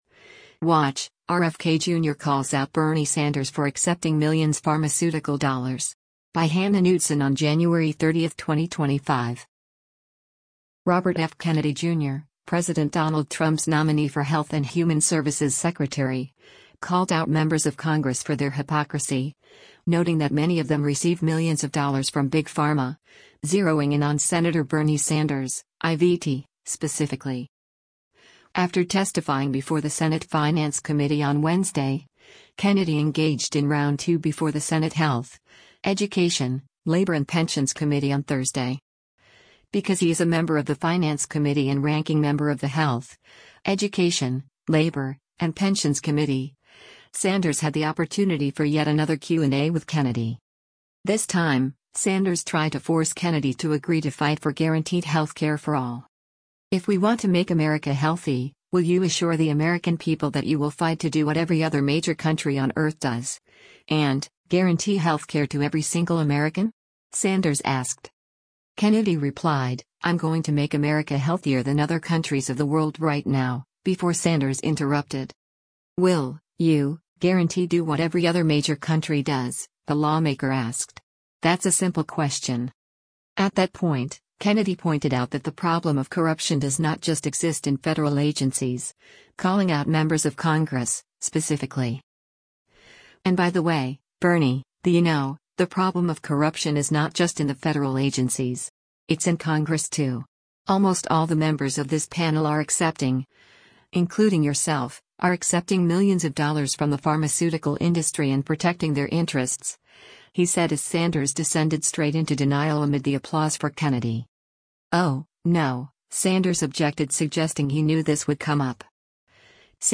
After testifying before the Senate Finance Committee on Wednesday, Kennedy engaged in round two before the Senate Health, Education, Labor and Pensions Committee on Thursday.
“And by the way, Bernie, the you know, the problem of corruption is not just in the federal agencies. It’s in Congress too. Almost all the members of this panel are accepting, including yourself, are accepting millions of dollars from the pharmaceutical industry and protecting their interests,” he said as Sanders descended straight into denial amid the applause for Kennedy.
Workers. Not a nickel from corporate PACs,” Sanders yelled.